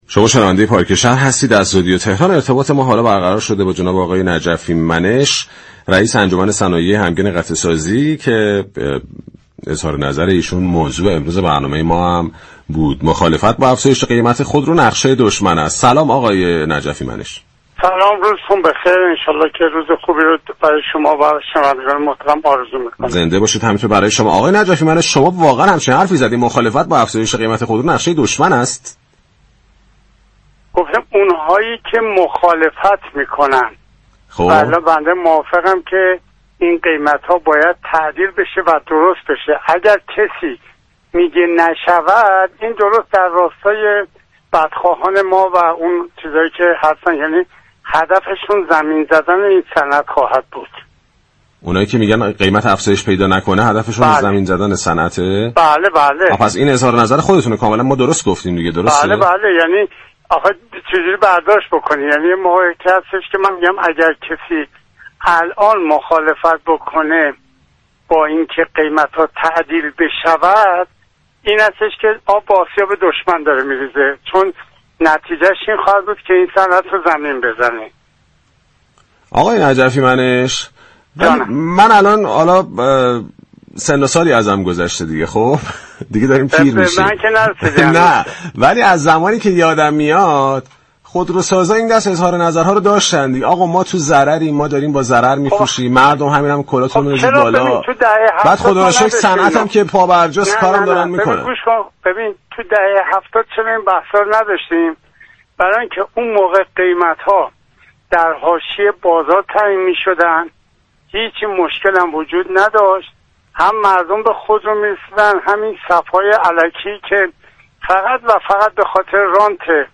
وی در گفتگو با برنامه پارك شهر 20 اردیبهشت با تایید اظهاراتش بیان كرد: قیمت ها باید تعدیل شود.